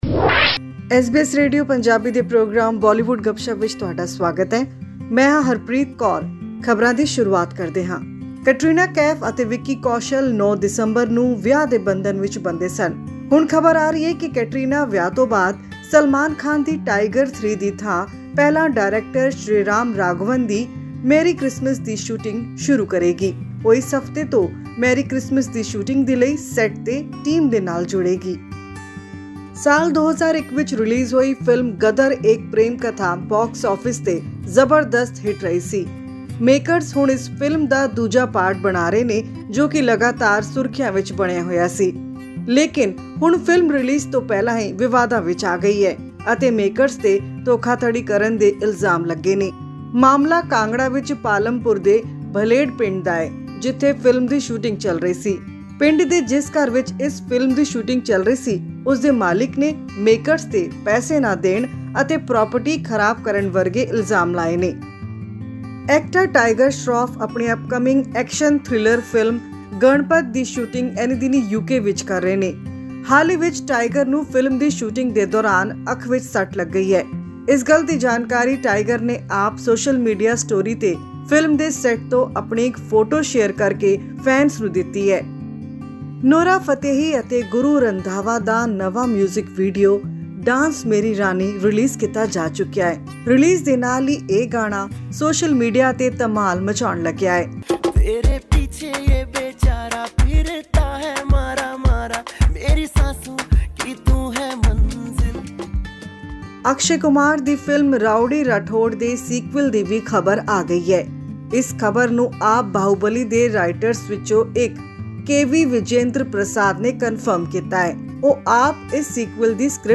83, the film is based on the Indian cricket team's triumph in the 1983 World Cup has been declared tax-free in Delhi, filmmaker Kabir Khan thanked Delhi chief minister Arvind Kejriwal for taking the initiative. Listen to this and much more right from Bollywood in our weekly news bulletin Bollywood Gupshup.